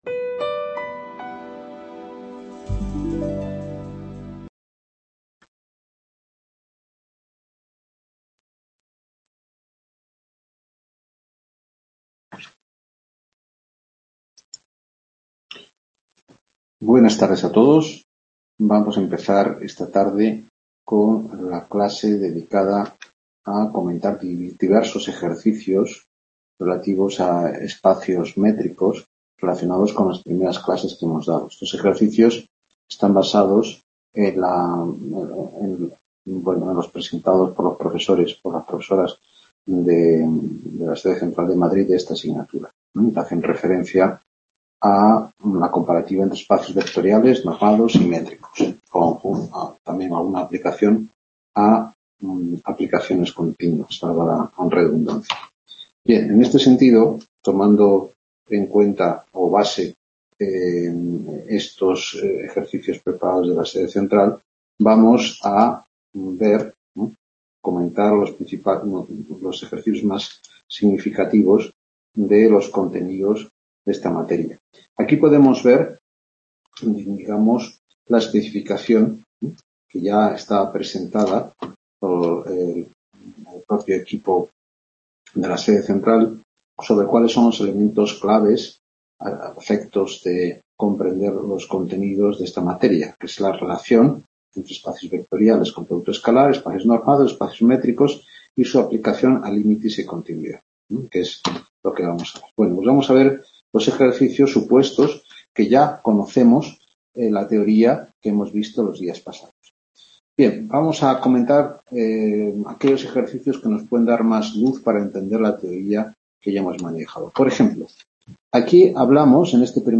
Clase práctica Espacios Métricos